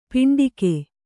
♪ piṇḍike